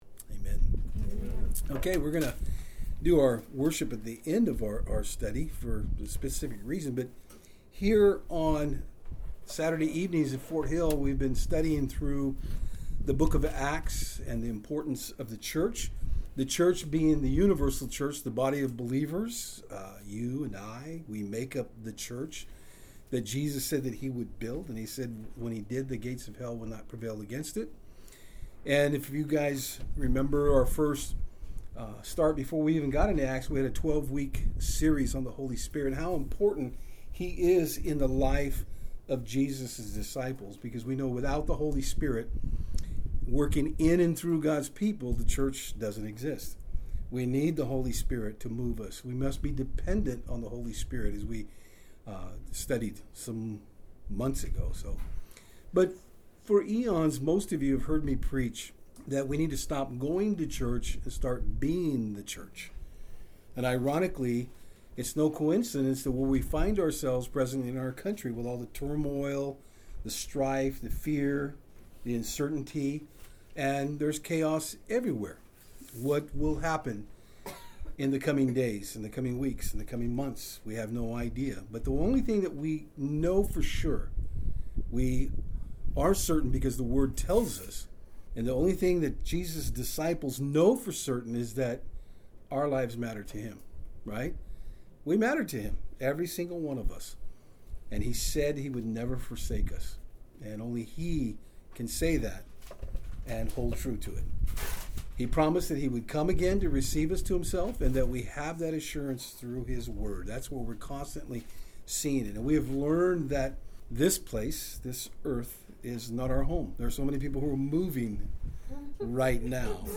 Service Type: Saturdays on Fort Hill